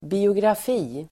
Uttal: [bi:ograf'i:]